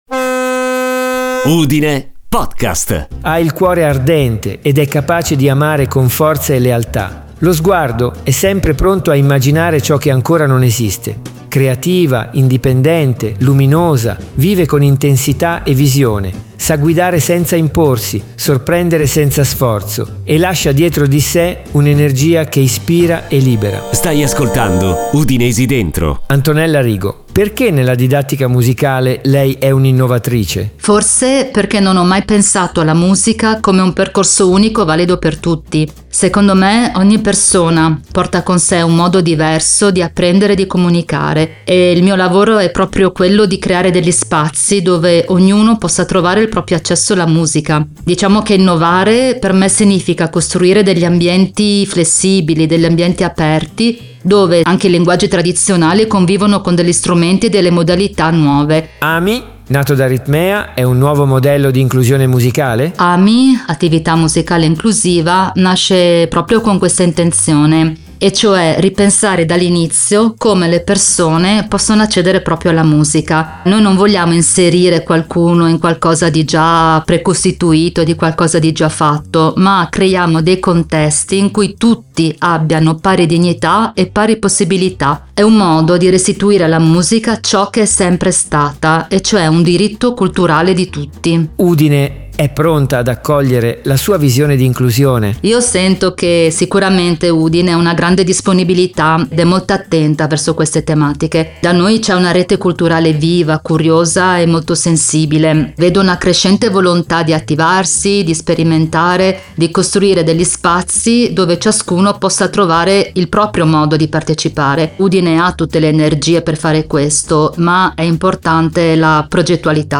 UDINESI DENTRO è un podcast originale